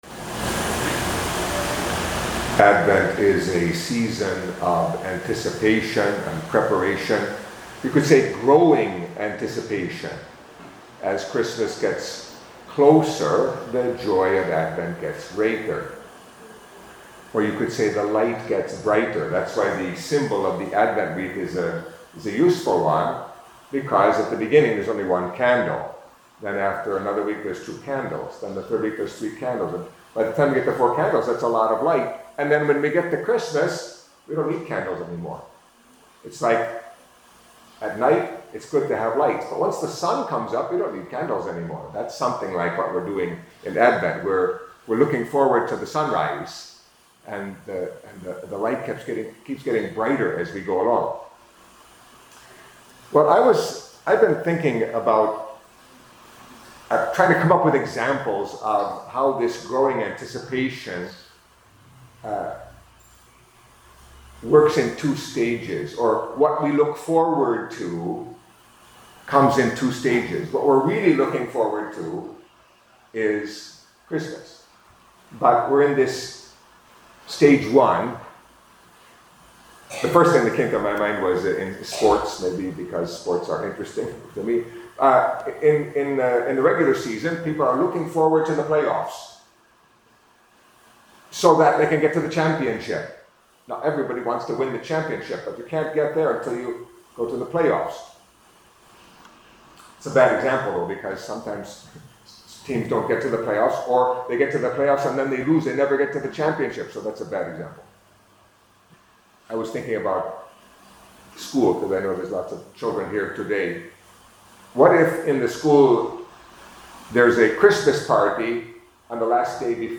Catholic Mass homily for Saturday of the Second Week of Advent